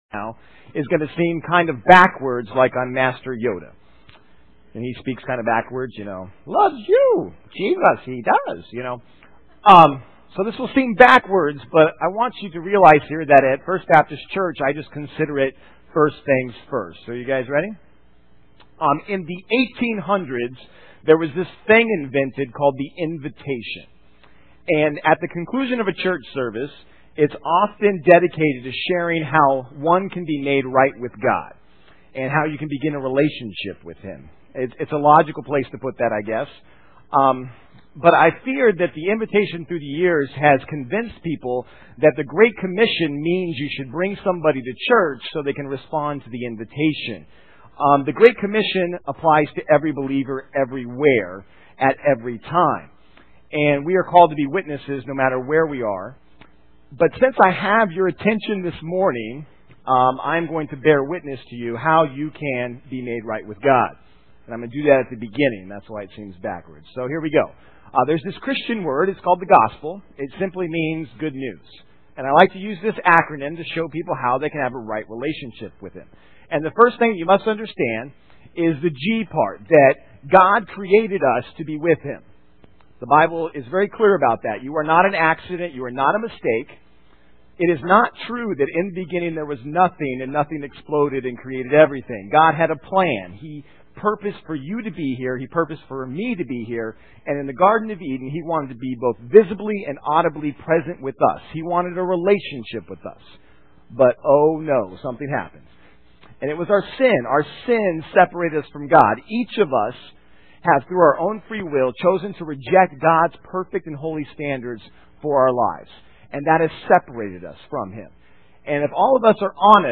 "Hospital or Country Club" - Main Service am